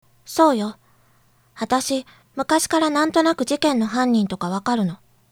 削った台詞。